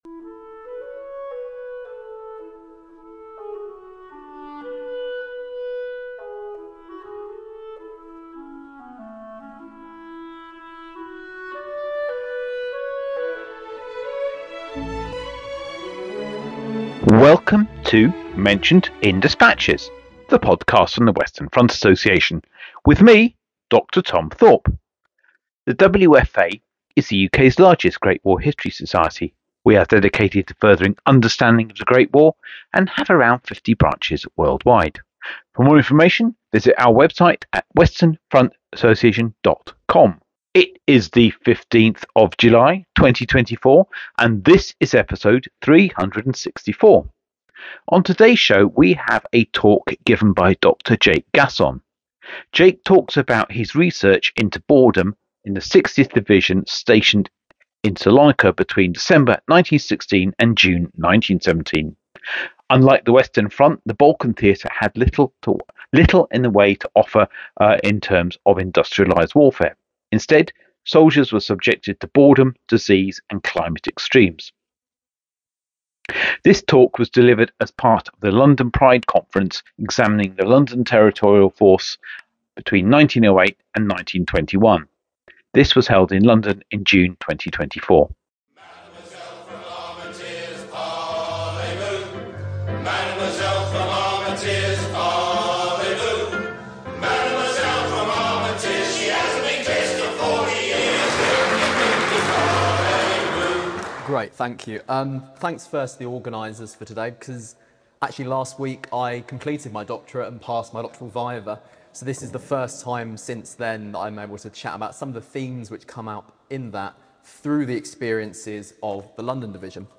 This talk was part of the London Pride Conference held in June 2024